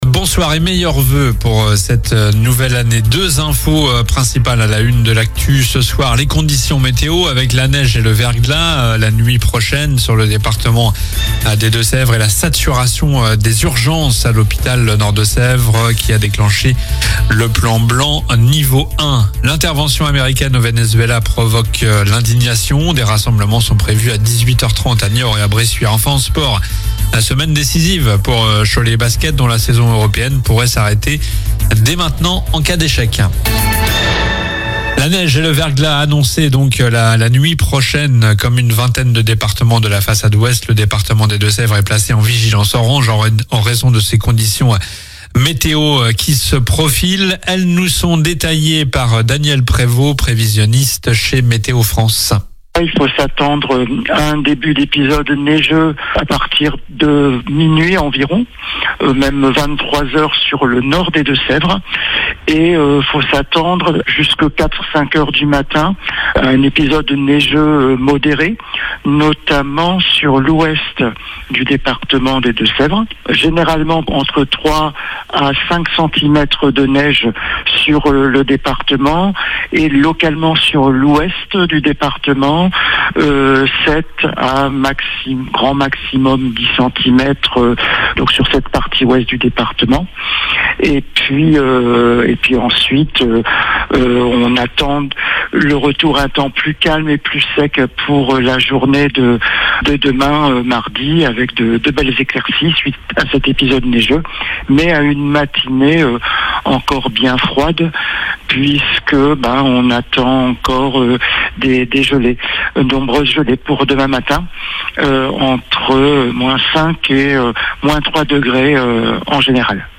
Journal du lundi 05 janvier (soir)